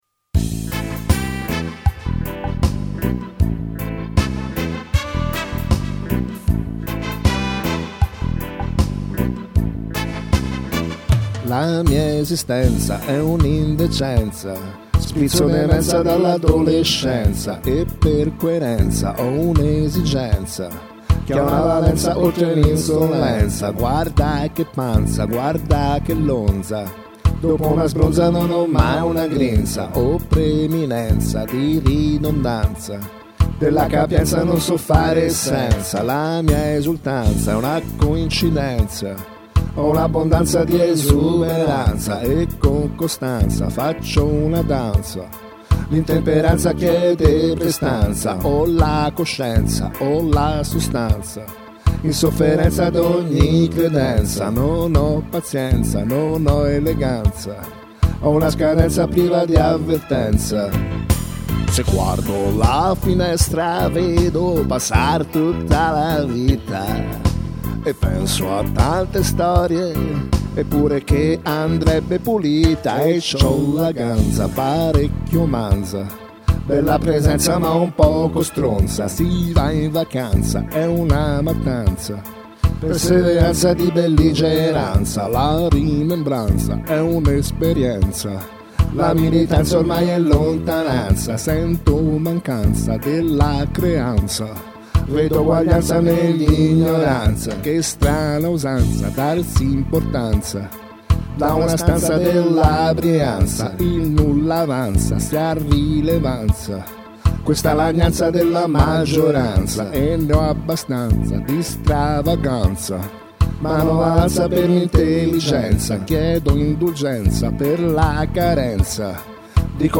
Poi gli ho dato una parvenza di forma e forse continua ad essere senza senso, comunque ecco qua questo reggae, che è; un genere che ho toccato poco negli anni.